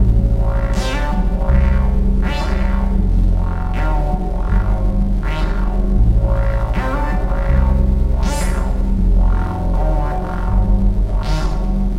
描述：喃喃自语的贝斯
Tag: 80 bpm Electronic Loops Bass Loops 2.20 MB wav Key : Unknown